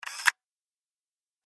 دانلود صدای دوربین 5 از ساعد نیوز با لینک مستقیم و کیفیت بالا
جلوه های صوتی